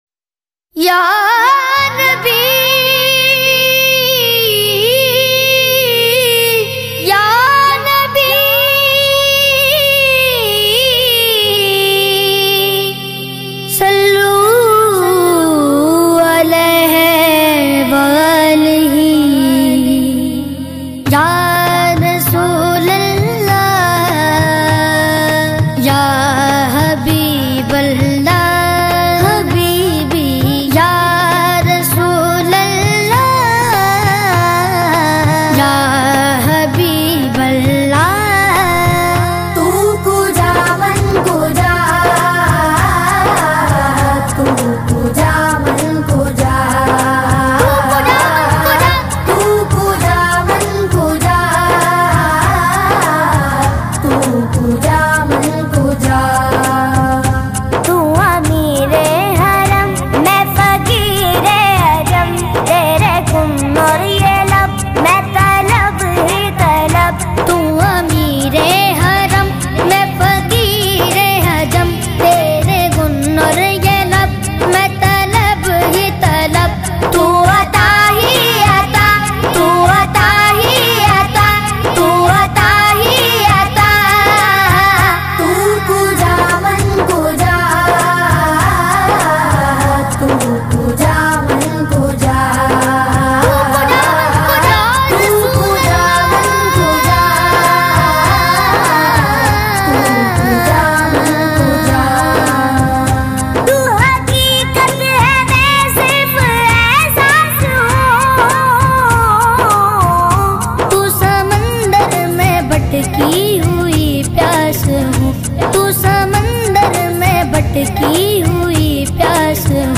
New Very Beautiful Naat Medley
Kids Kalam